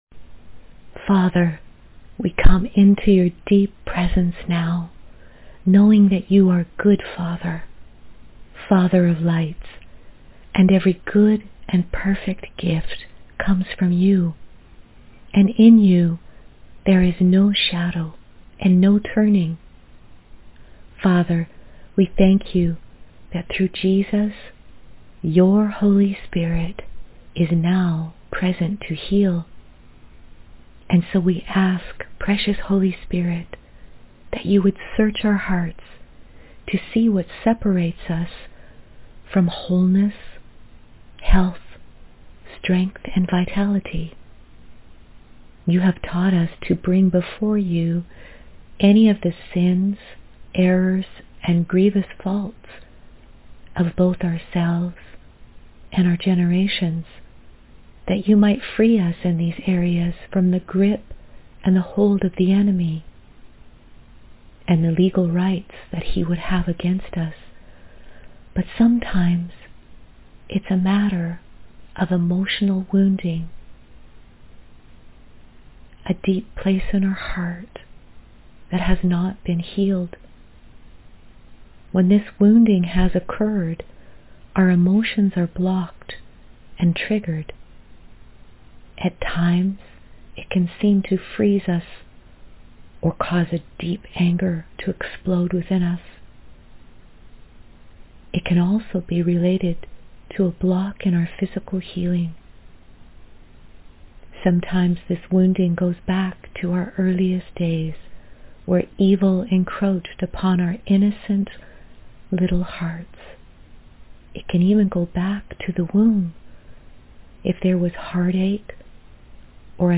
Inner Healing Prayer:
Inner-Healing-Prayer.mp3